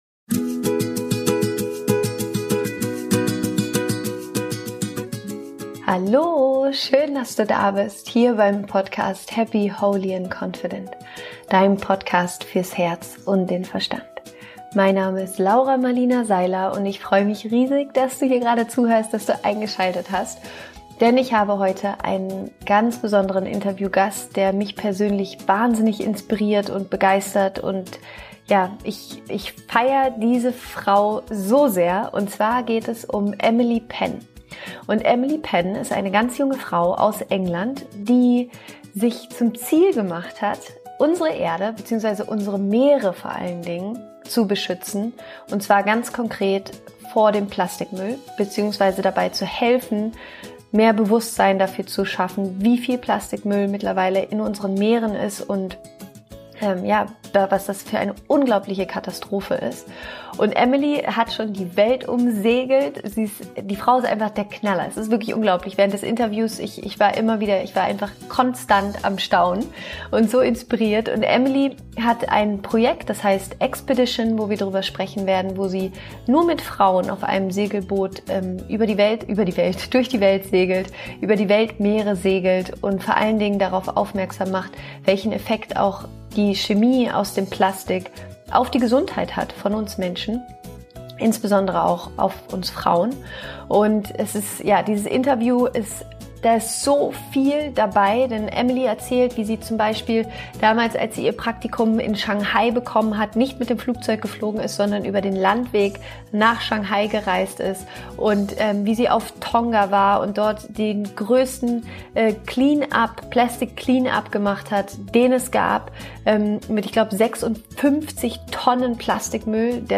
Bitte beachte, dass dieses Interview auf Englisch ist (dt. Untertitel folgen in Kürze auf Youtube)